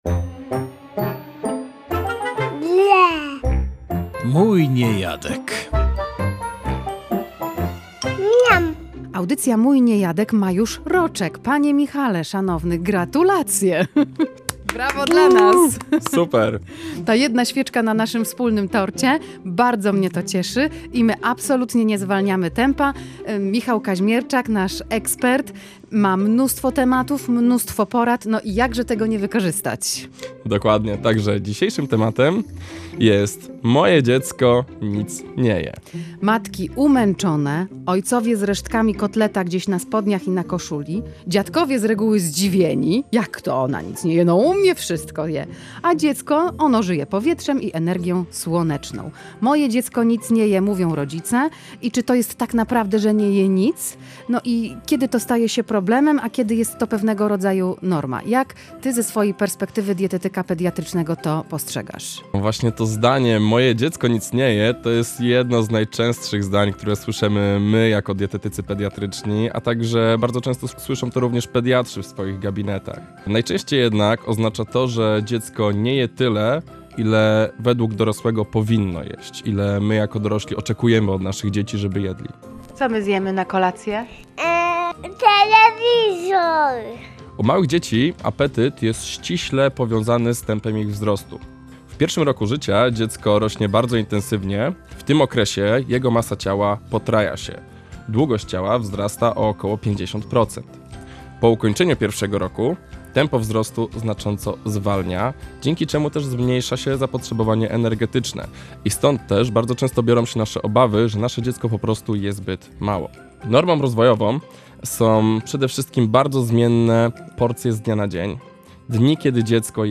„Moje dziecko nic nie je”… czy na pewno? Dietetyk komentuje